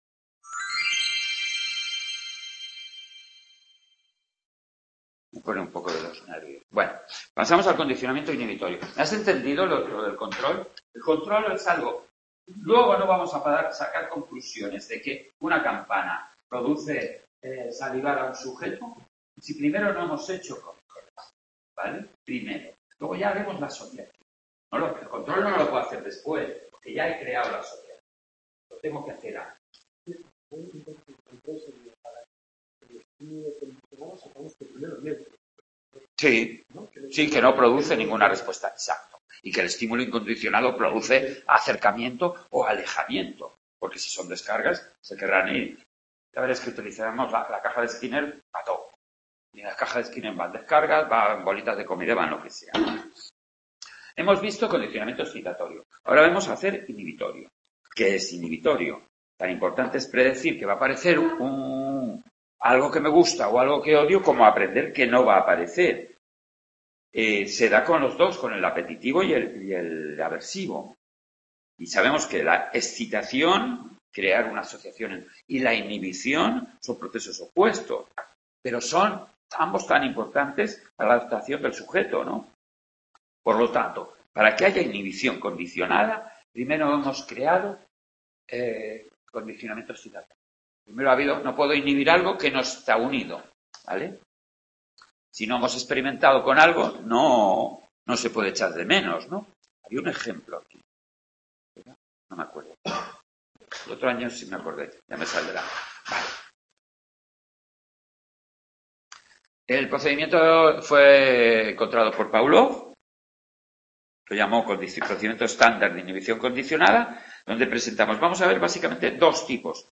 en el Centro Asociado UNED de Sant Boi de Llobregat (Barcelona)